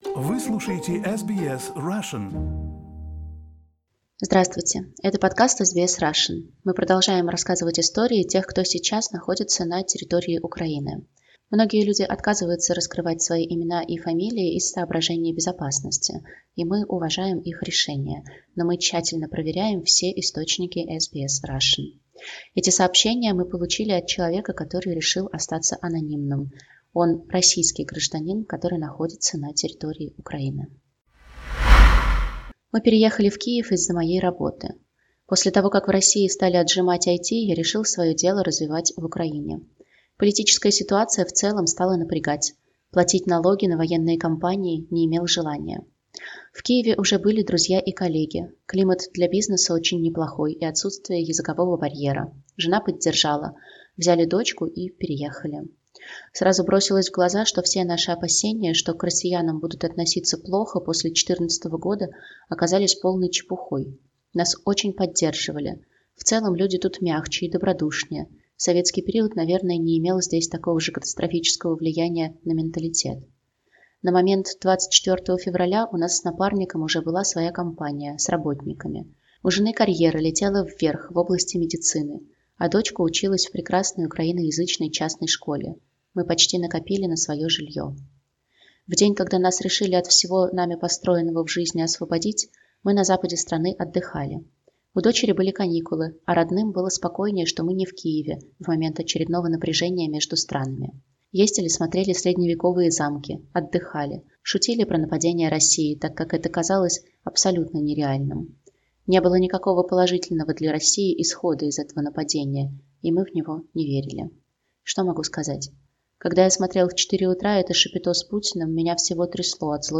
В аудио и текстовых сообщениях жители Украины рассказывают о том, на что стала похожа их жизнь в последние дни.